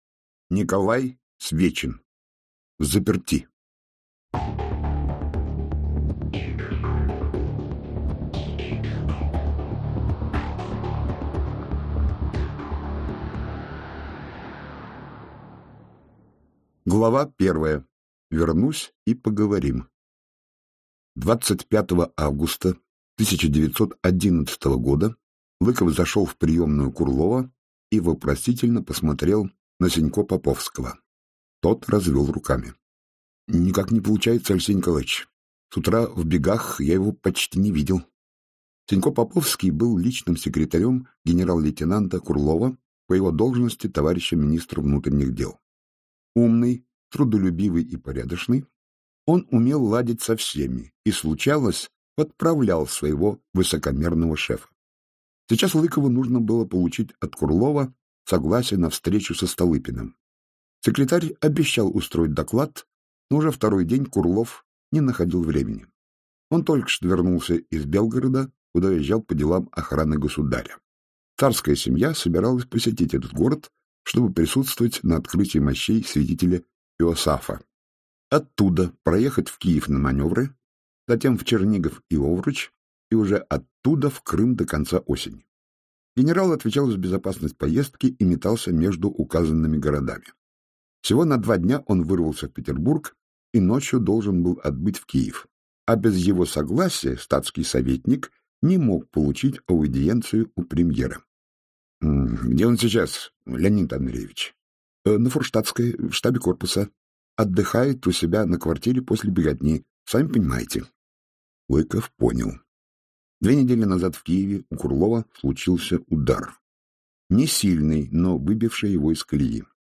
Аудиокнига Взаперти | Библиотека аудиокниг